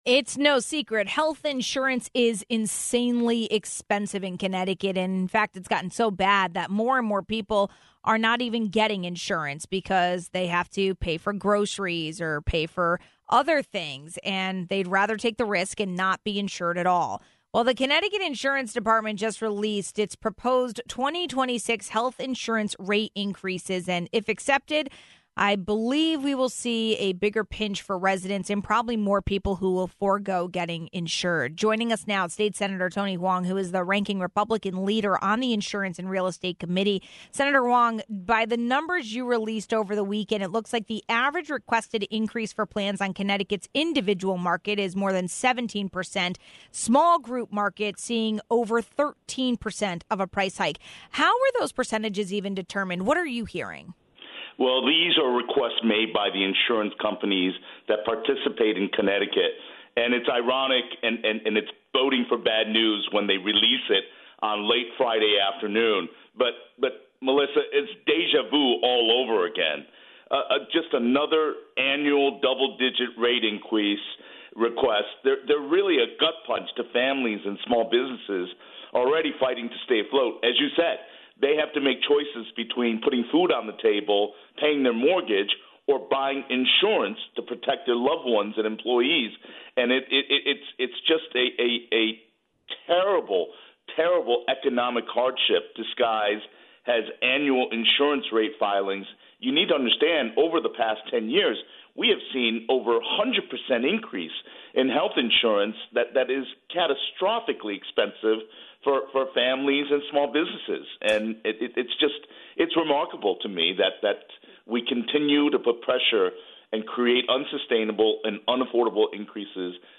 We spoke with State Senator Tony Hwang, the Ranking Republican leader on the Insurance and Real Estate Committee, about the proposals.